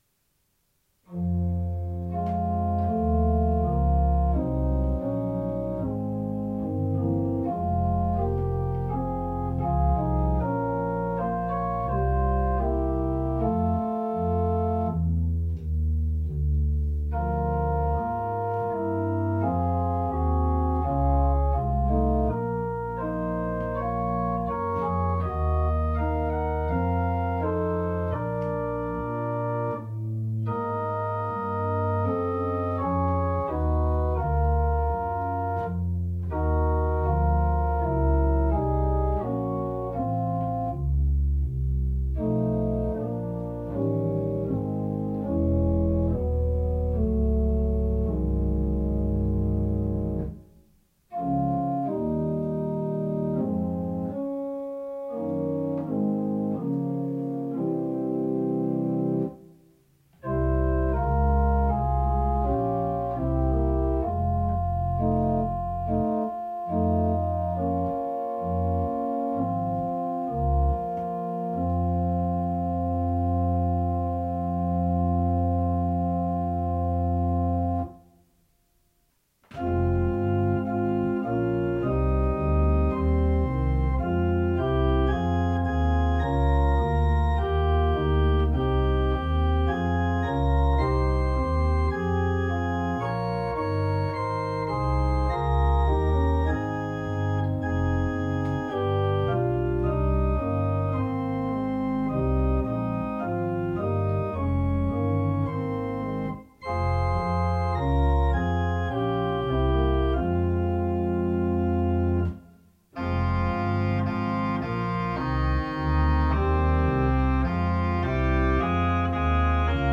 Ziel war es, passend zum Vorspiel, eine Art „Walking Bass“ zu unterlegen und auf jeder Viertelnote eine andere Harmonie zu bringen.
Beide Lieder habe ich auf vier Manualen gespielt und konnte so die Klangfarben ohne Unterbrechung wechseln.